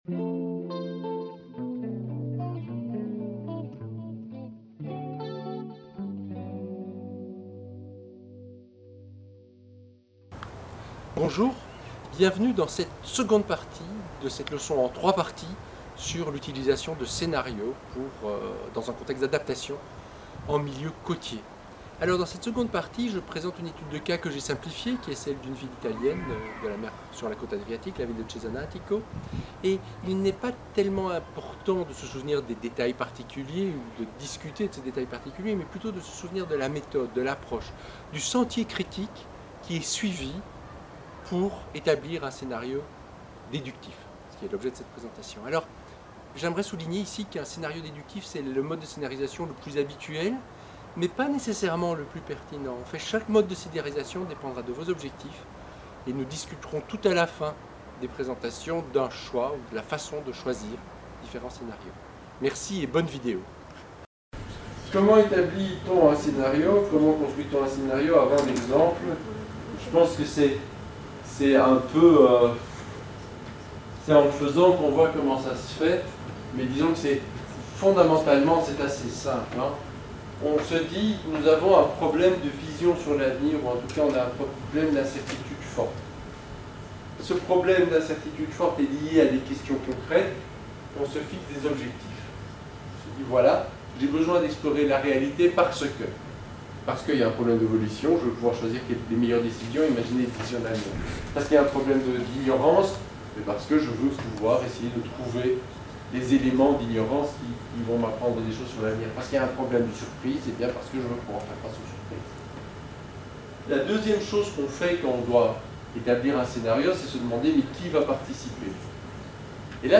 Il s'agit de la deuxième partie d'une leçon en trois parties sur la scénarisation prospective dans un contexte d'adaptation côtière aux changements climatiques.